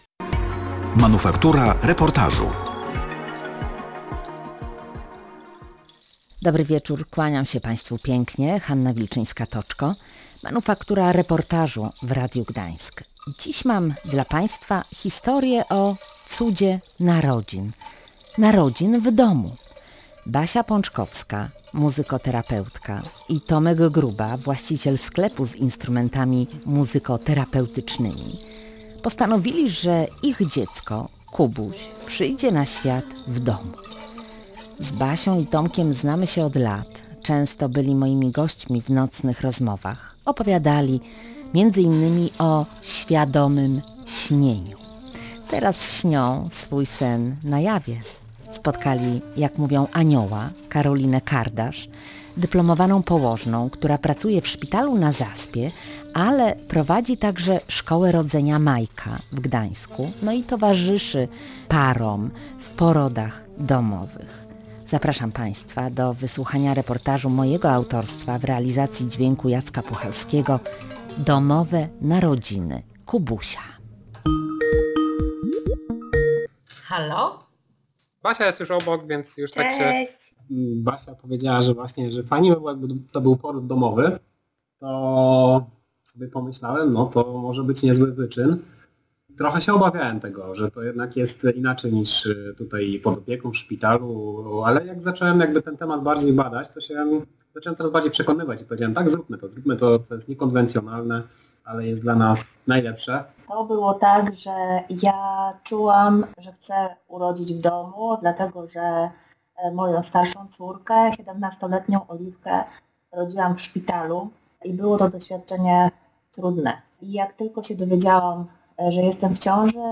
gra przepięknie na instrumencie o nazwie Rav Vast Drum.
/audio/dok3/manufaktura250520.mp3 Tagi: poród reportaż